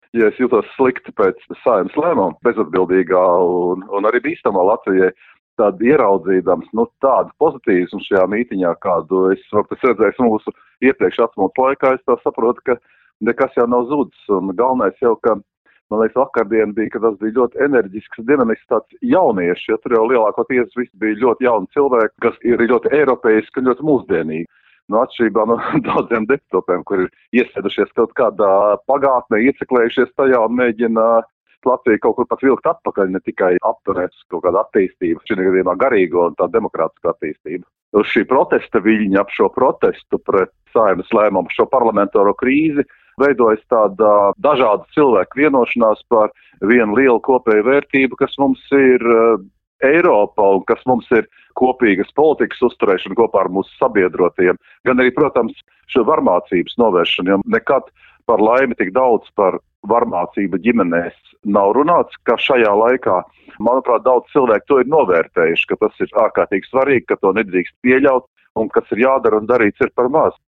Intervijā Skonto mediju grupai Dainis Īvāns pastāstīja, ka pēc protesta Domā laukumā, pārņēmušas pozitīvas sajūtas.